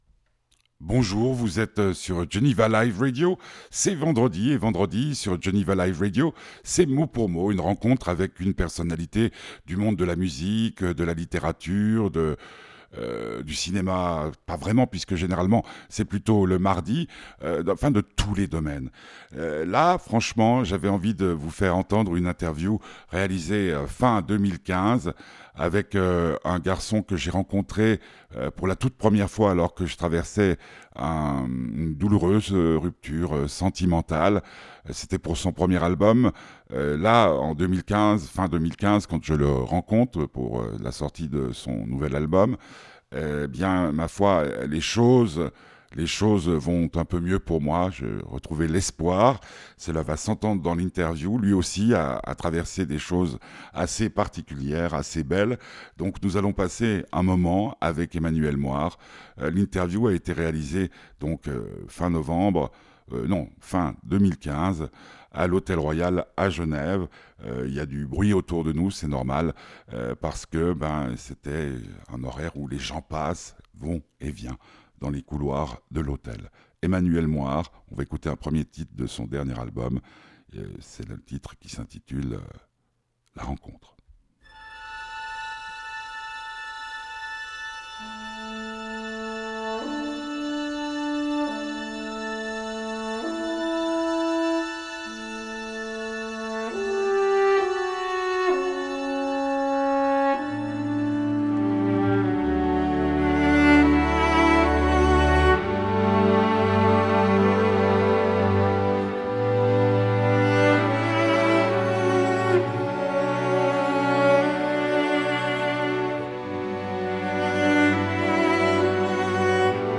Interview d'Emmanuel Moire